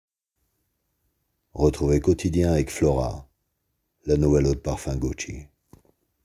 45 - 55 ans - Basse